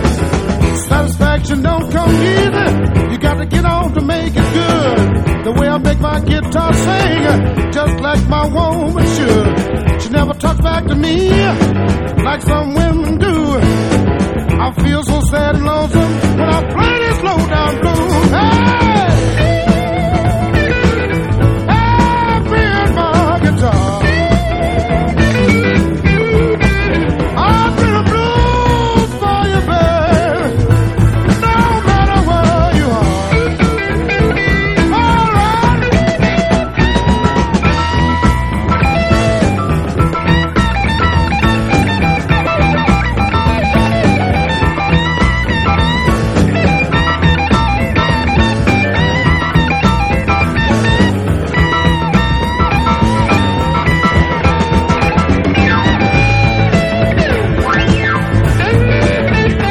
¥3,280 (税込) ROCK / 80'S/NEW WAVE.
跳ね系ピアノ入りの激ポップな不良カントリー・ロック
サックスが咽ぶ大人っぽいジャジー・インスト